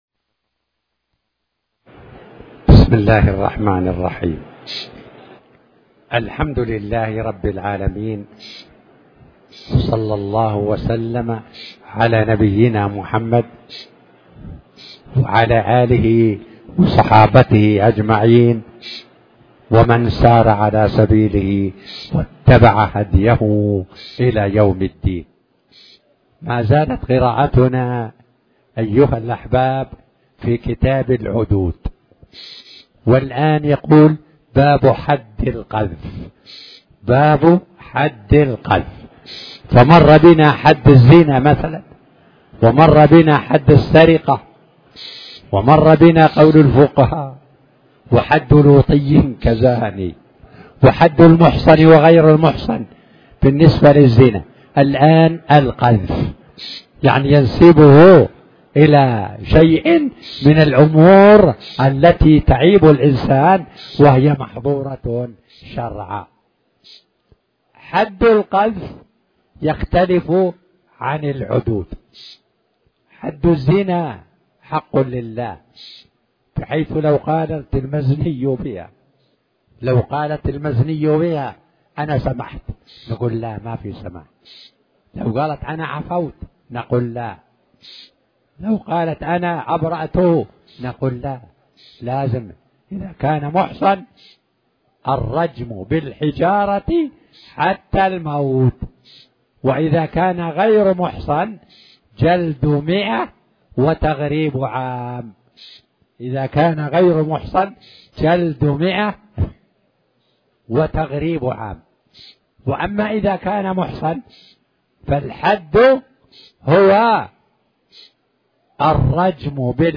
تاريخ النشر ٣ ربيع الثاني ١٤٤٠ هـ المكان: المسجد الحرام الشيخ